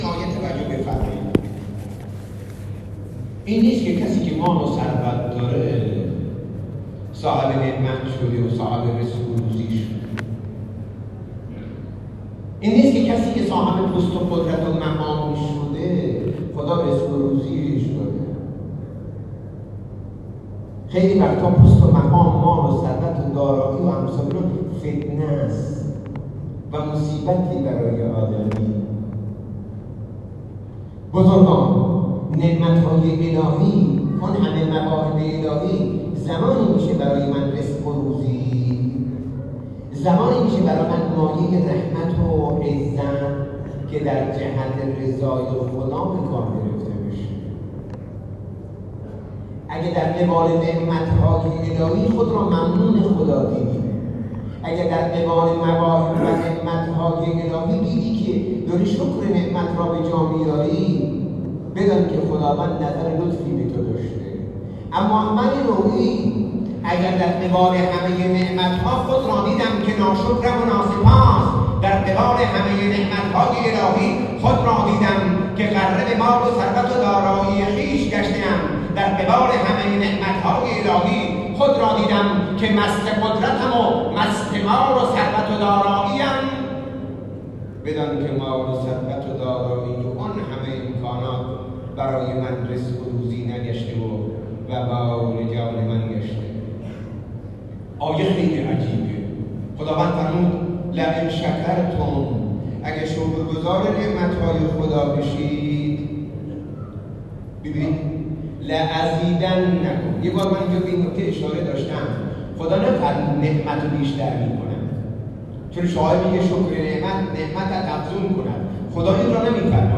سخنان خطیب نماز جمعه را از طریق فایل های صوتی زیر می توانید بشنوید: